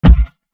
DHL BEAT SWITCH KICK 2.mp3